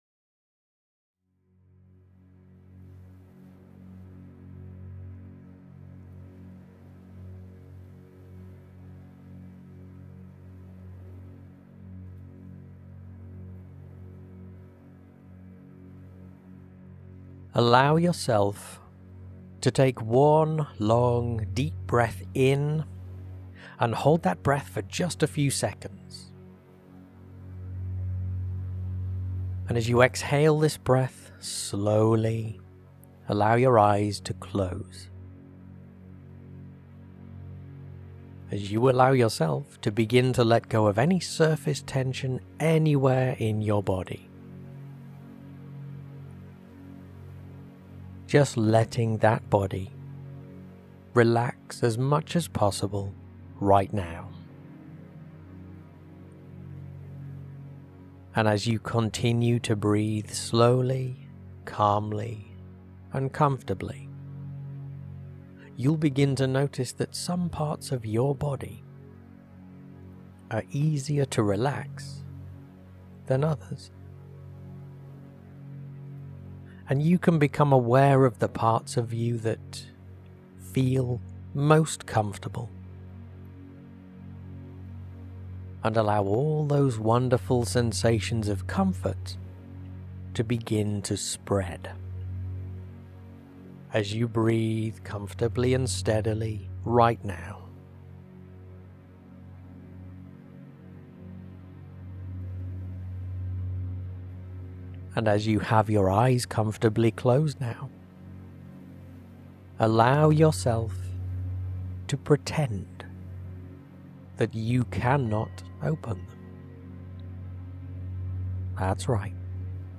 Below you’ll find an MP3 download of the relaxing and empowering meditation technique we used at the DC-Fit Dance Degustation Event.